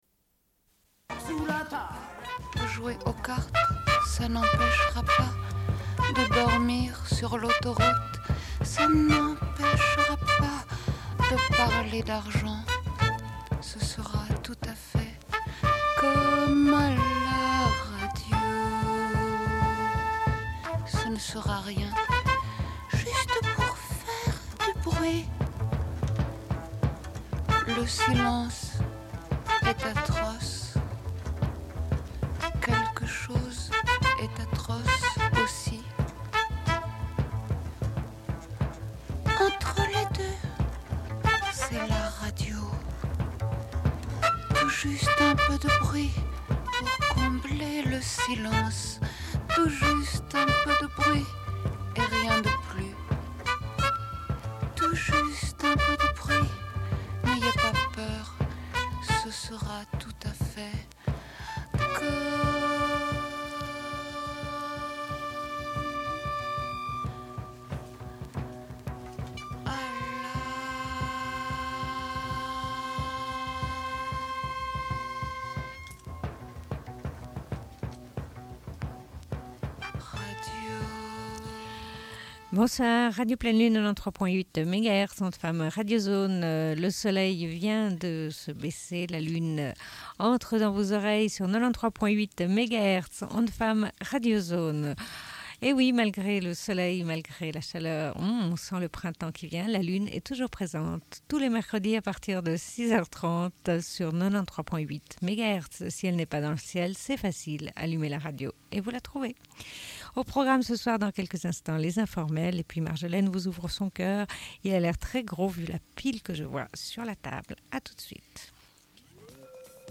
Bulletin d'information de Radio Pleine Lune du 28.02.1996 - Archives contestataires
Une cassette audio, face B